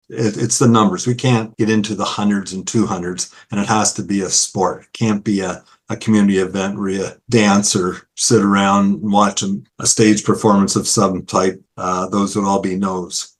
A hefty price tag of nearly half a million dollars is what’s needed to bring the Stephen Arena up to code compliance – that’s according to an architectural report presented to South Huron Council on Monday (June 17th).